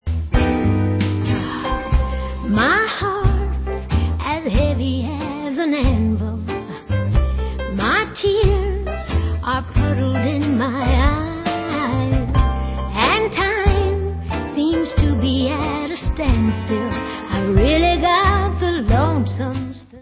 sledovat novinky v oddělení Rockabilly/Psychobilly